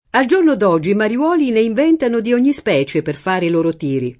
mariolo [mari-0lo] (oggi lett. o napol. mariuolo [mariU0lo]) s. m. — es.: mariolo sì, diceva don Ferrante, ma profondo [mari-0lo S&, di©%va dqn ferr#nte, ma pprof1ndo] (Manzoni); al giorno d’oggi i mariuoli ne inventano di ogni specie per fare i loro tiri [